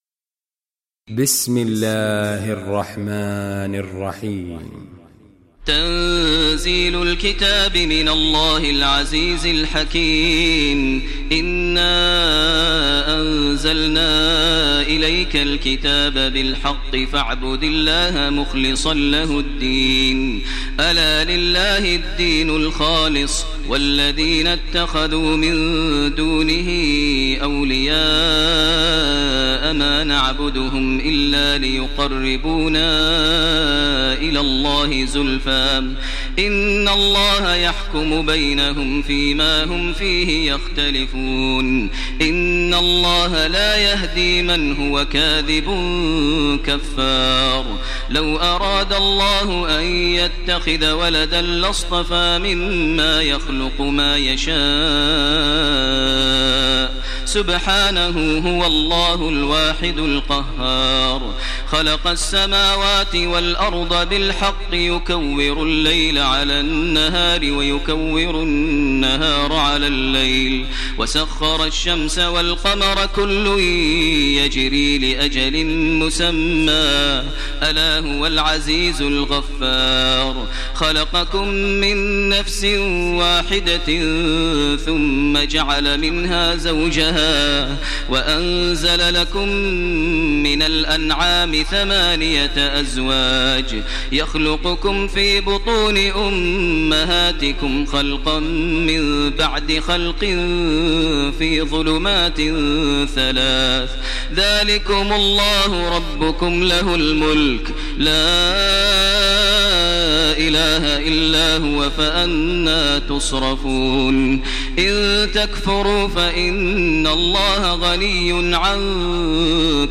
Surah Az Zumar Recitation by Maher al Mueaqly
Surah Az Zumar, listen online mp3 tilawat / recitation in the voice of Sheikh Maher al Mueaqly.